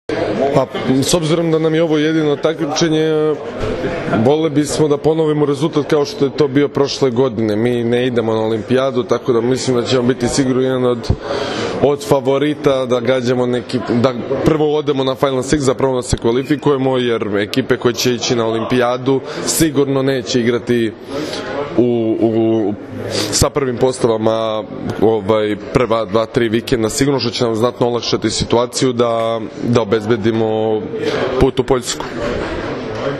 IZJAVA UROŠA KOVAČEVIĆA